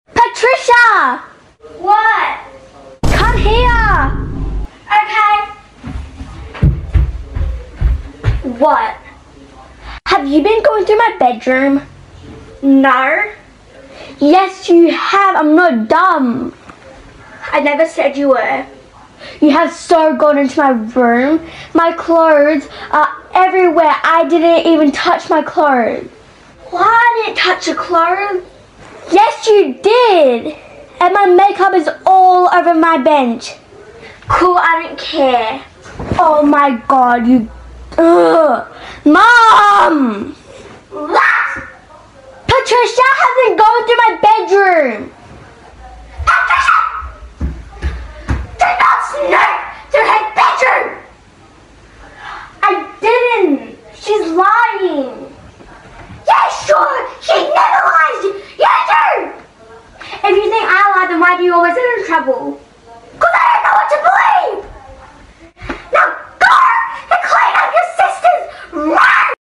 slowed the audio down for their real voices